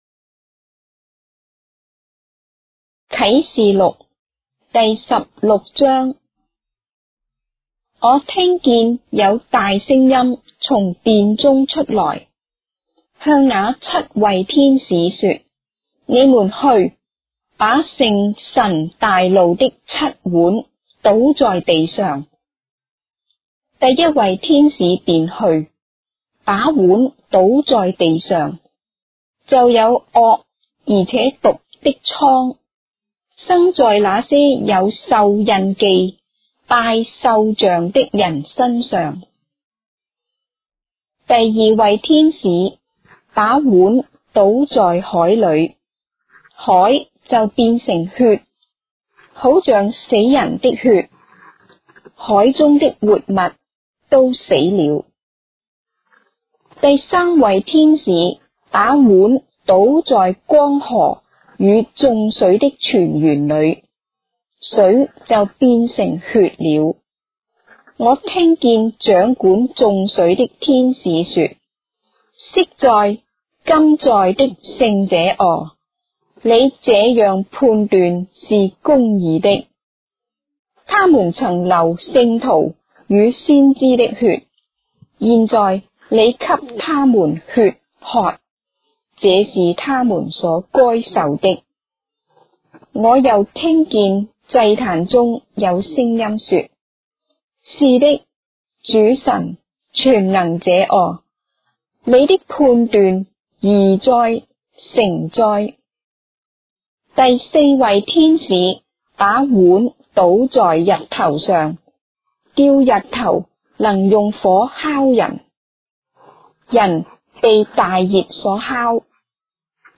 章的聖經在中國的語言，音頻旁白- Revelation, chapter 16 of the Holy Bible in Traditional Chinese